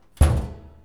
Index of /DESN275/soundLibrary/doorSounds/Door_Slams
DOOR_076.AIF